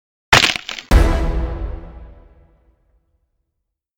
Play SCP Neck Snap Valorant Kill Sound - SoundBoardGuy
scp-neck-snap-valorant-kill-sound.mp3